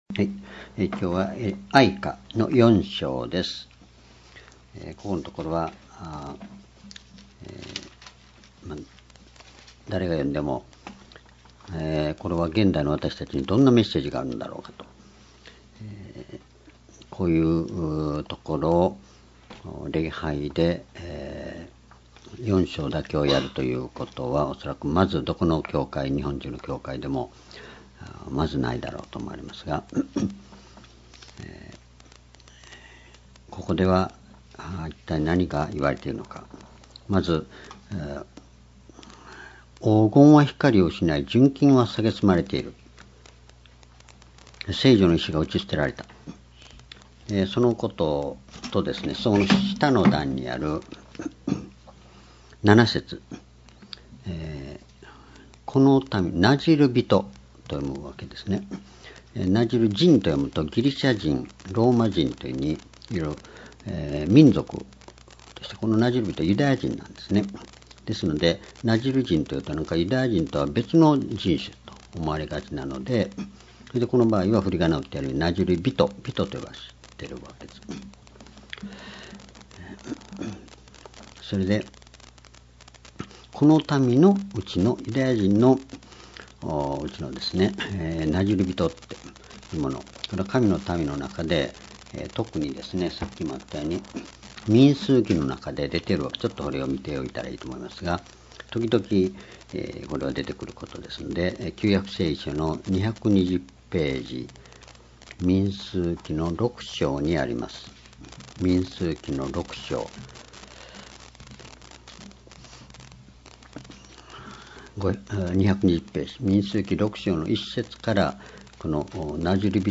主日礼拝日時 2018年5月1日 夕拝 聖書講話箇所 「裁きと復興」 哀歌4章 ※視聴できない場合は をクリックしてください。